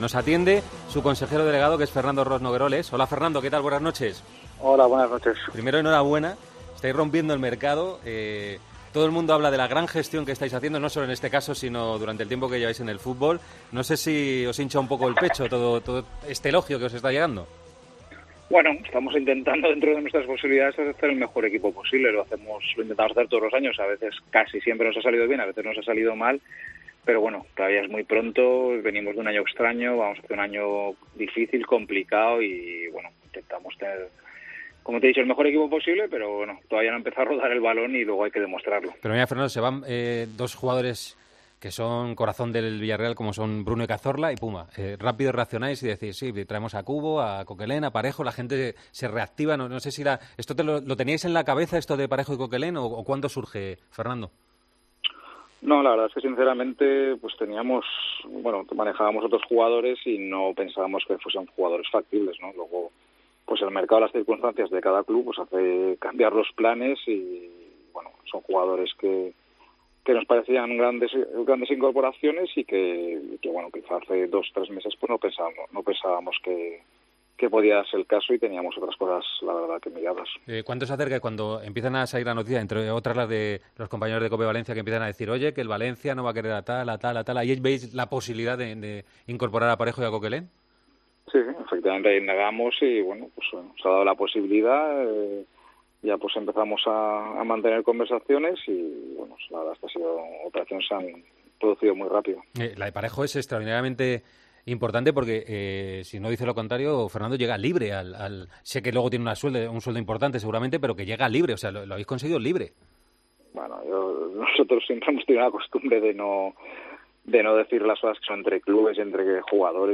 El Partidazo de COPE llamó a Villarreal.